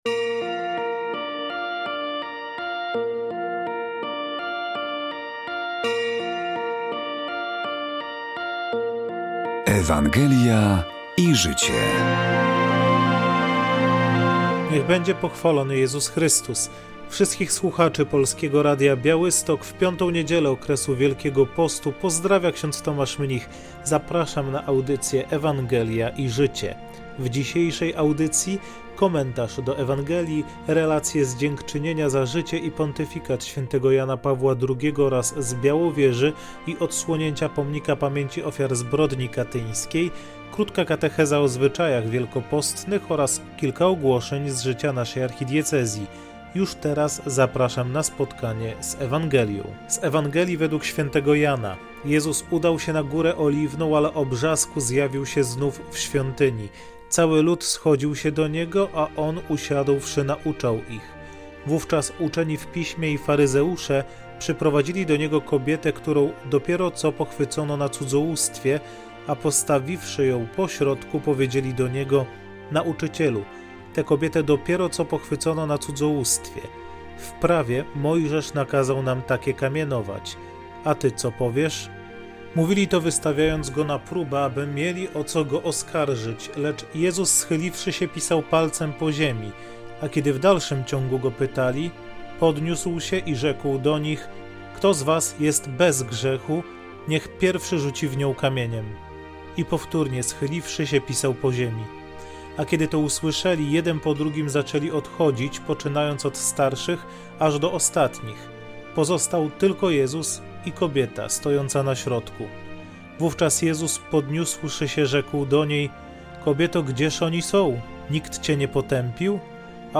W audycji rozważanie do niedzielnej Ewangelii, relacje z dziękczynienia za życie i pontyfikat św. Jana Pawła II oraz z Białowieży i odsłonięcia Pomnika Pamięci Ofiar Zbrodni Katyńskiej, krótka katecheza o zwyczajach wielkopostnych oraz kilka ogłoszeń z życia naszej Archidiecezji.